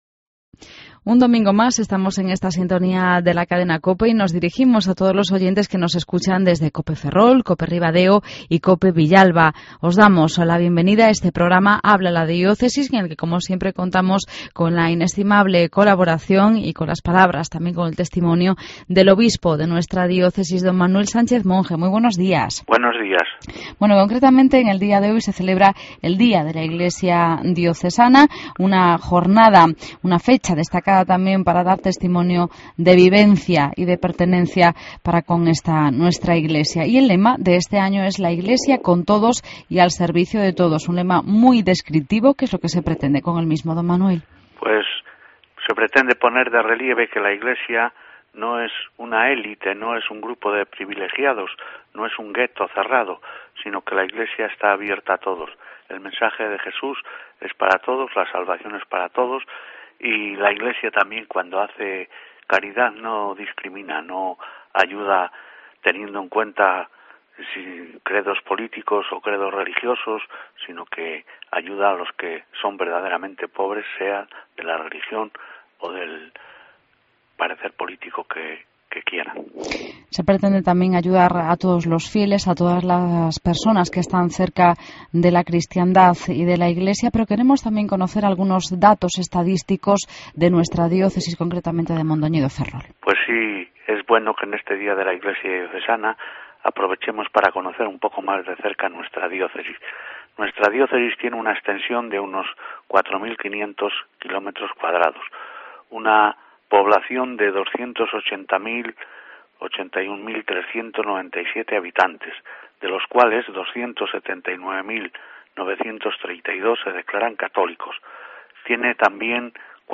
Redacción digital Madrid - Publicado el 17 nov 2013, 11:42 - Actualizado 02 feb 2023, 00:31 1 min lectura Descargar Facebook Twitter Whatsapp Telegram Enviar por email Copiar enlace El obispo de nuestra diócesis, Monseñor Sánchez Monje, nos habla del Día de la Iglesia Diocesana, que se celebra en esta jornada.